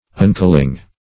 Search Result for " uncling" : The Collaborative International Dictionary of English v.0.48: Uncling \Un*cling"\, v. i. [1st pref. un- + cling.] To cease from clinging or adhering.